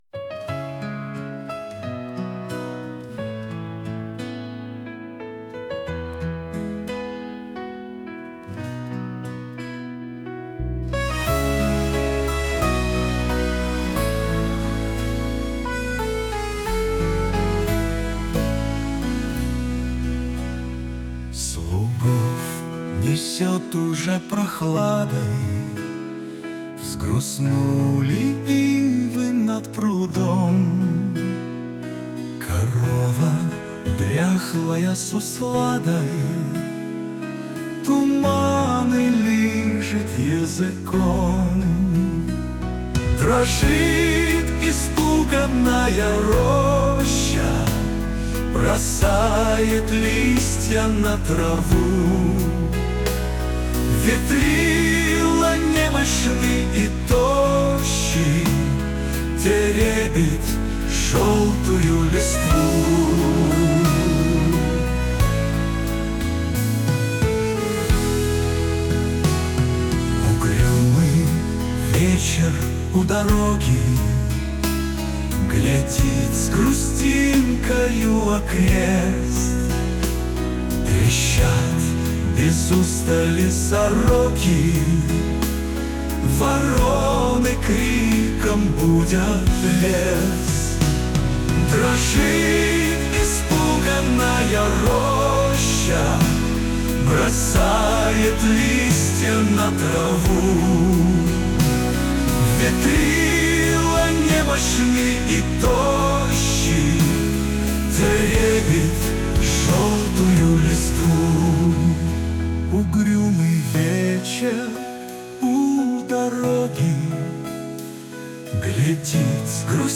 Аудиостихотворение С ЛУГОВ НЕСЁТ УЖЕ ПРОХЛАДОЙ... слушать